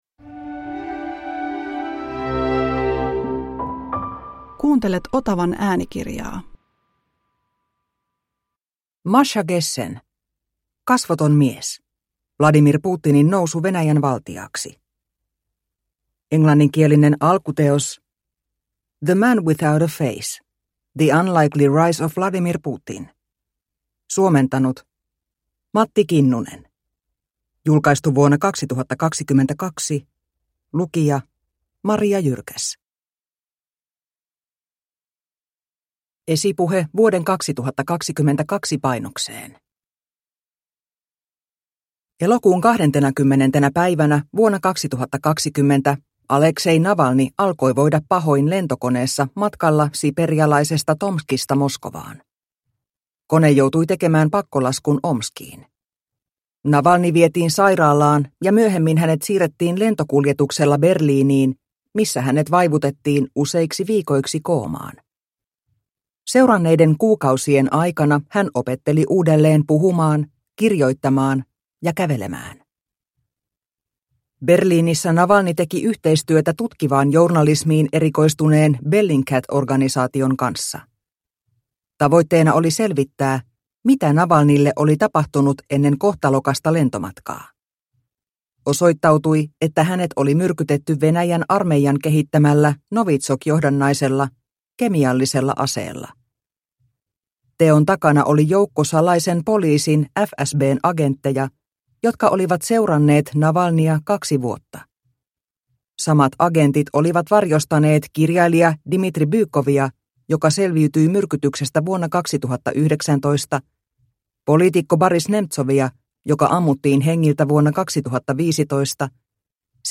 Kasvoton mies – Ljudbok – Laddas ner